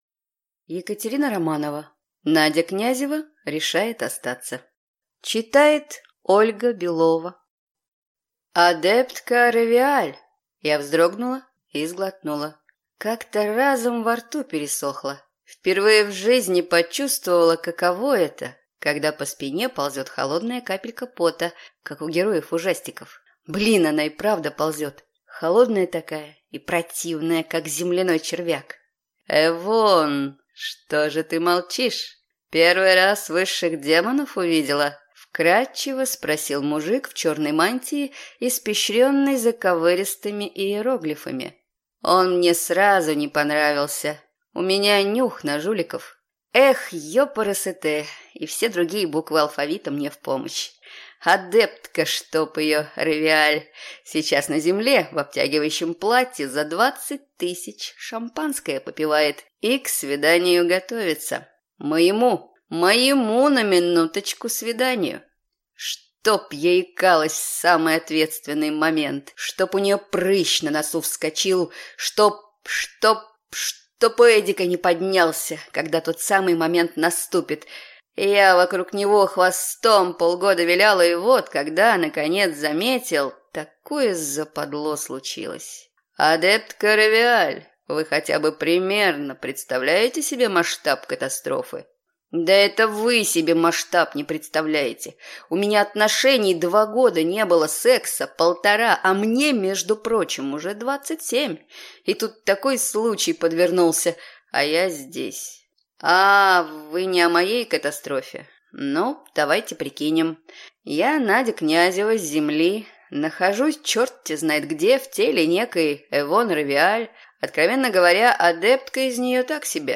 Аудиокнига Надя Князева решает остаться | Библиотека аудиокниг